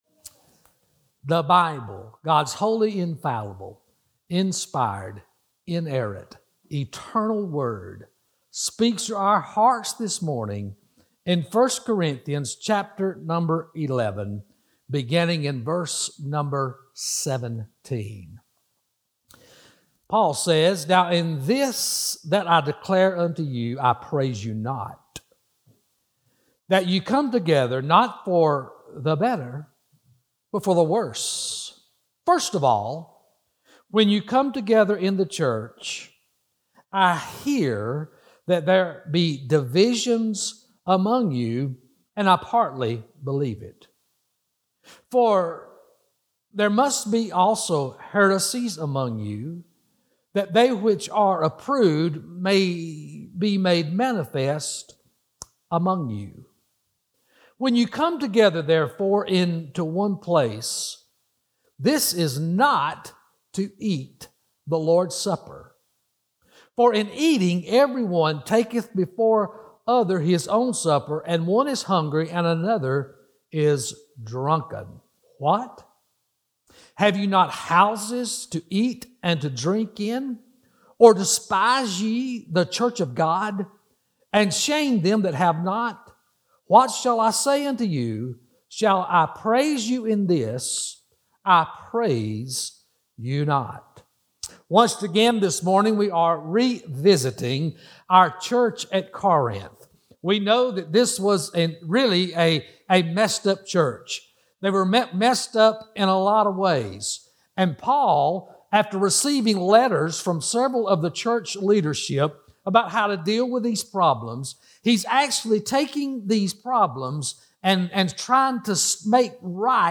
Sermons | Kansas Baptist Church